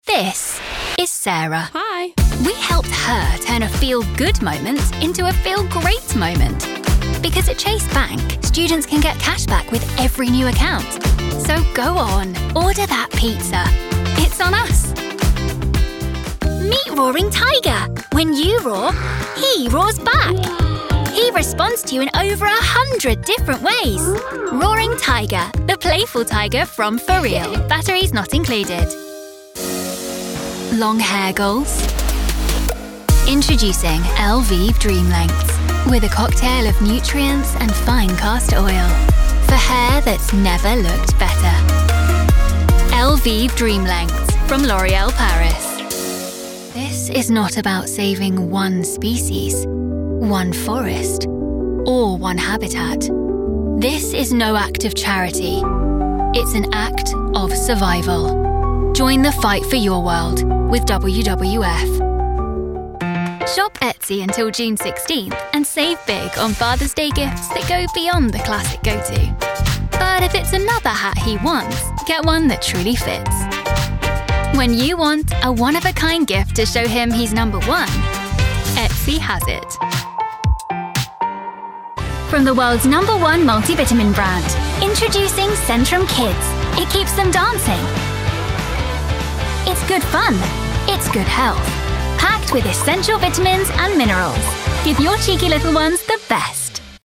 English (British)
Commercial, Young, Playful, Friendly, Soft
Commercial